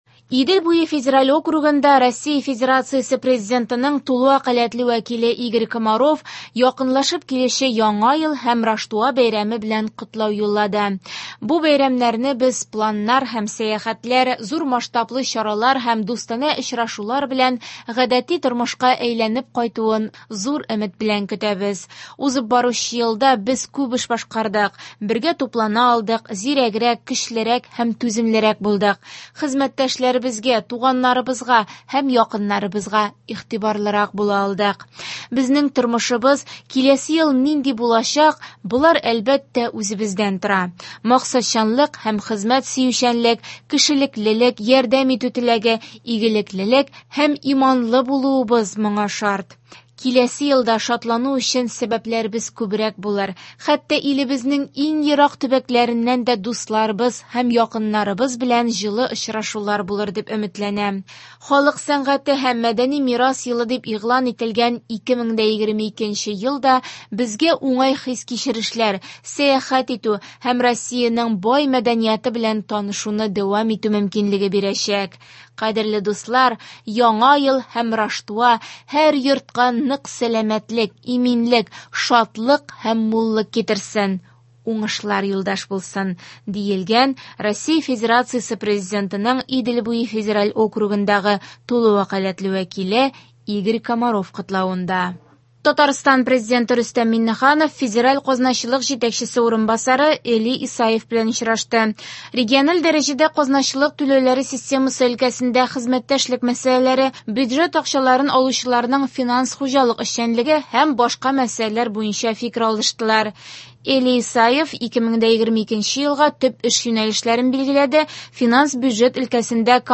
Яңалыклар (31.12.21)